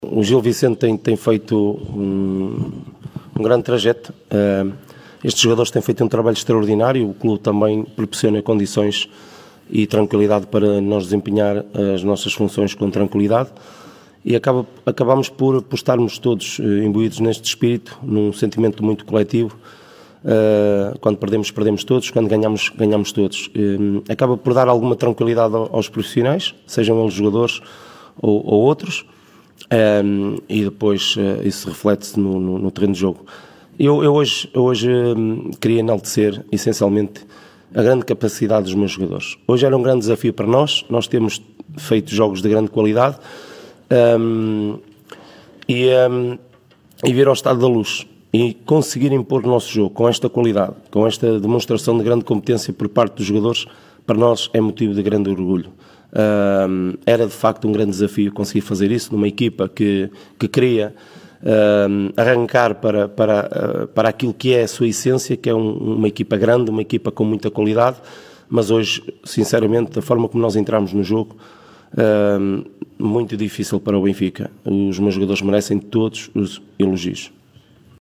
No final do jogo, Ricardo Soares, treinador dos barcelenses, enalteceu o jogo da sua equipa.